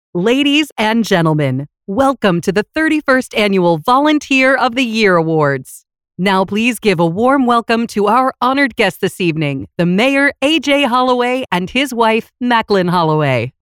Live Announce - Female Voice of God (VOG)
Pre-recorded or live, in-person voiceover talent for your event.
Awards Ceremony